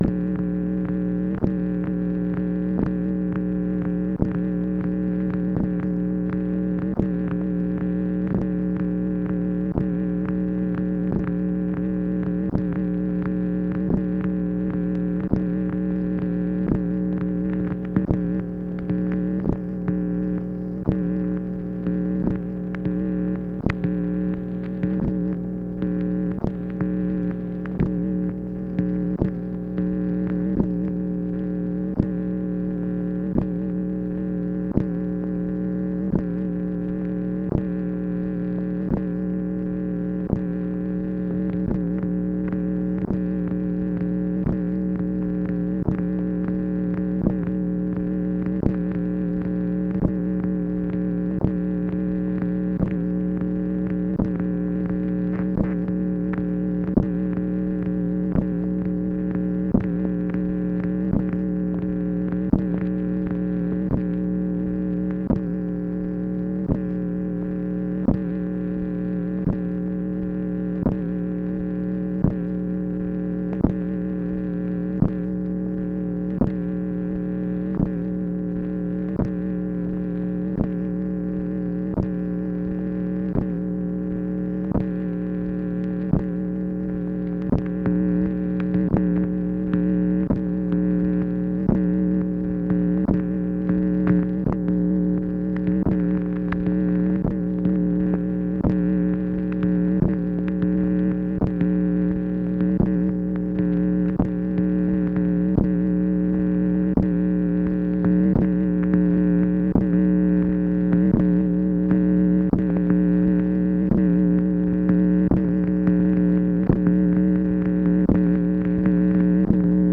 MACHINE NOISE, February 12, 1964
Secret White House Tapes | Lyndon B. Johnson Presidency